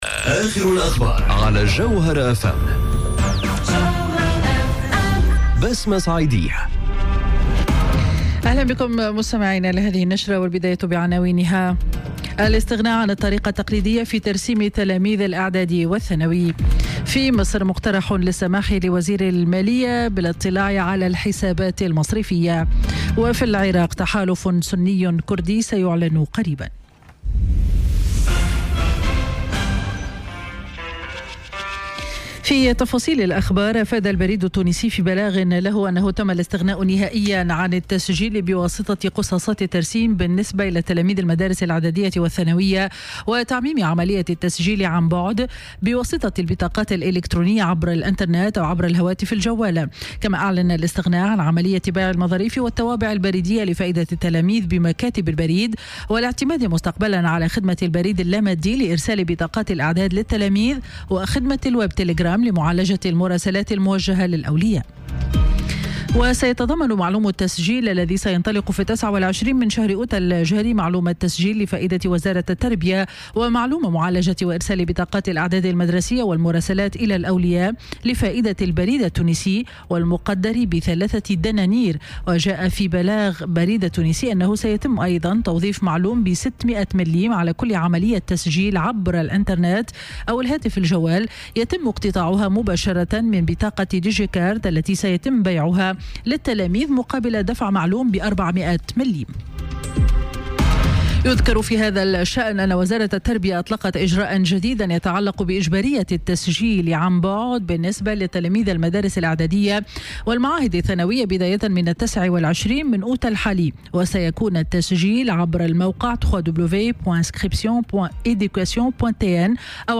نشرة أخبار منتصف النهار ليوم الأحد 26 أوت 2018